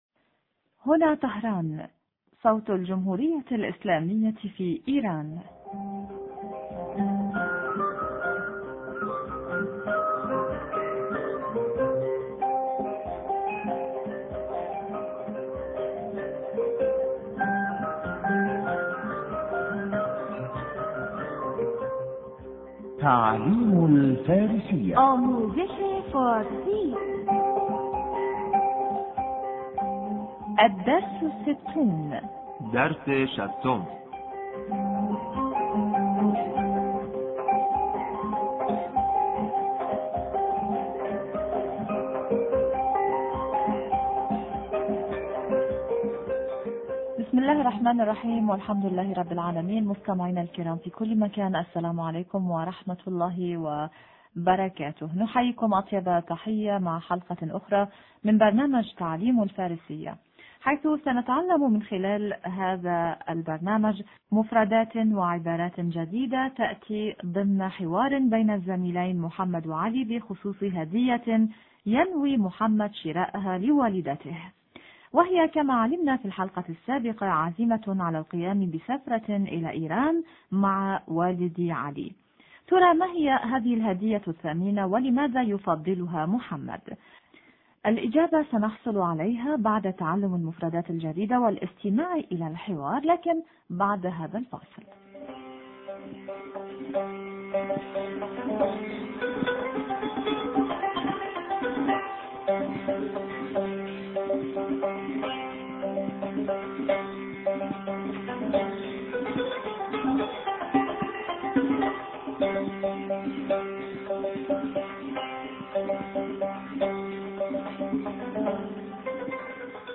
إذاعة طهران- تعلم الفارسية- بحث حول شراء الهدية